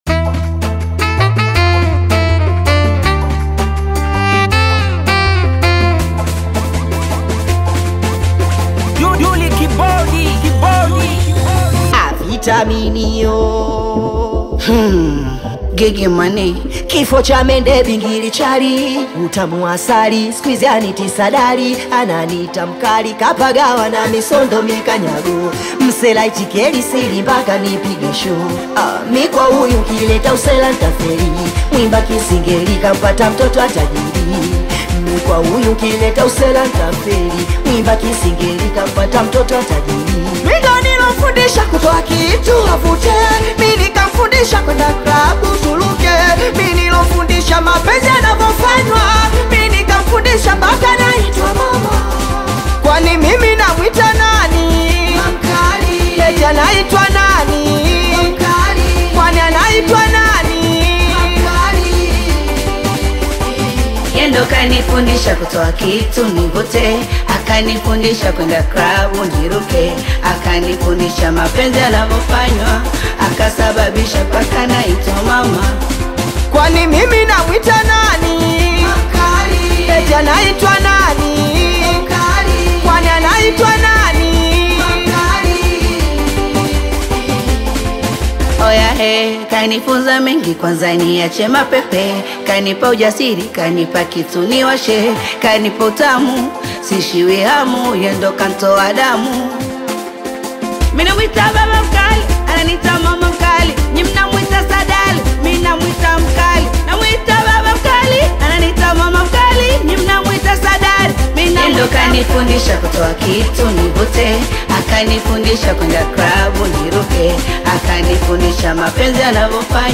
Bongo Flava